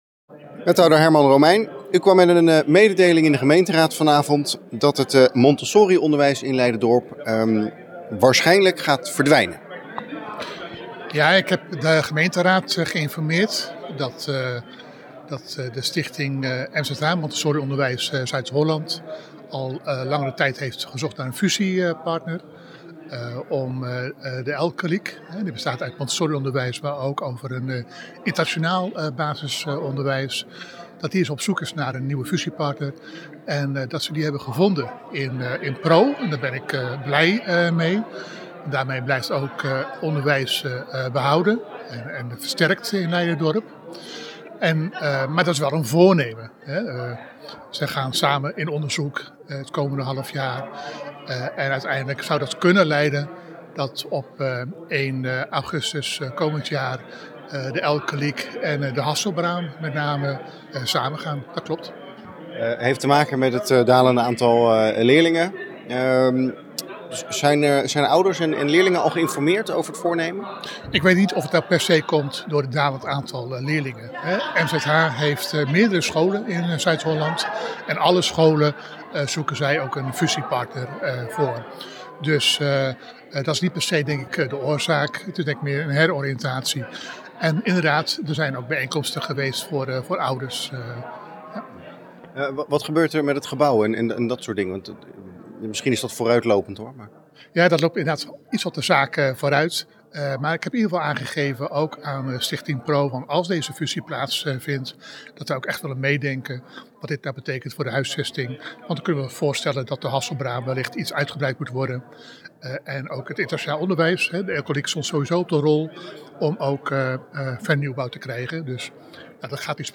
Wethouder Herman Romeijn over het mogelijk verdwijnen van het montessorionderwijs in het dorp: